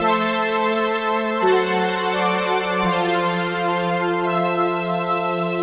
Index of /musicradar/80s-heat-samples/85bpm
AM_VictorPad_85-A.wav